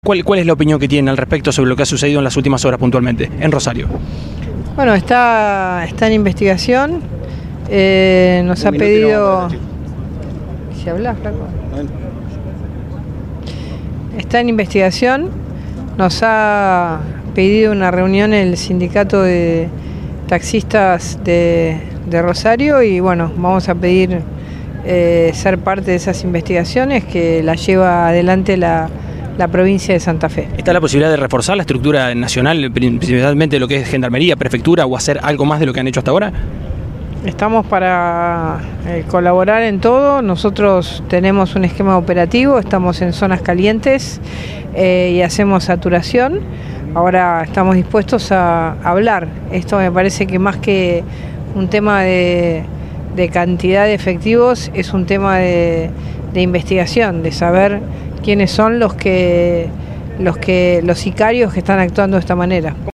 En diálogo con el móvil de Cadena 3 Rosario desde Expoagro, Bullrich aseguró que los hechos están en “investigación”, y comentó que “el sindicato de Peones de Taxi de Rosario nos ha pedido una reunión”.